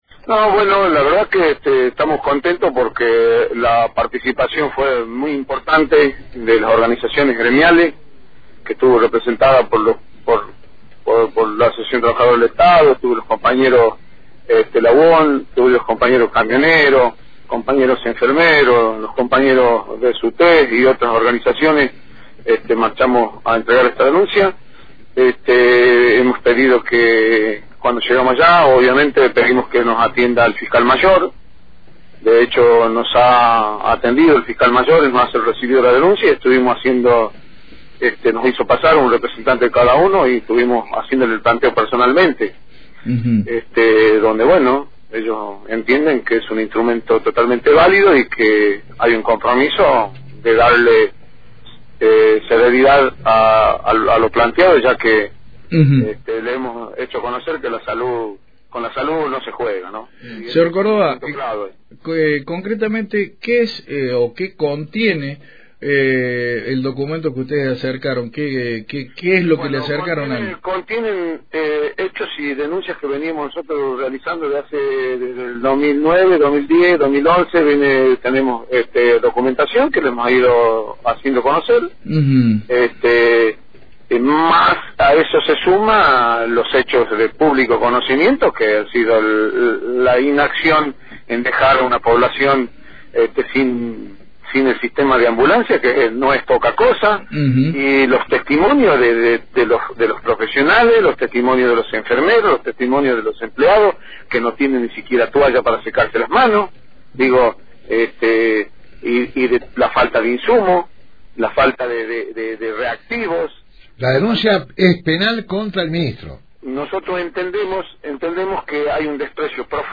en diálogo con Radio fueguina